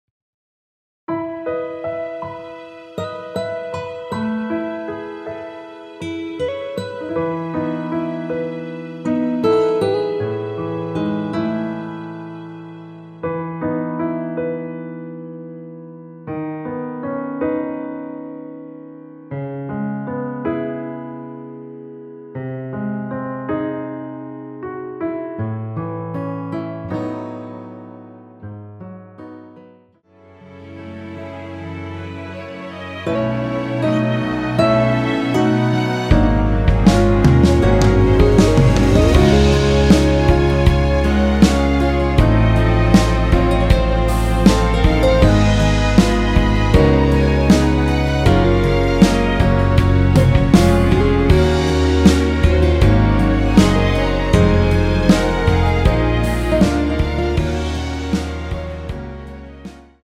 1절 “자신 있는 걸~” 다음 2절 후렴의 “내리는 비를 막아~”로 진행 됩니다.(본문의 가사참조)
앞부분30초, 뒷부분30초씩 편집해서 올려 드리고 있습니다.
중간에 음이 끈어지고 다시 나오는 이유는